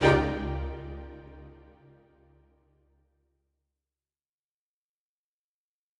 Strings Hit 3 Spiccato
Bring new life to your videos with professional orchestral sounds.
A spiccato is one of the shortest and fastest sounds that a string instrument can make.  In this sample, you hear four sections of four different instruments from the orchestra which are violins, violas, violoncellos and double basses.
Strings-Hit-3-Spiccato.wav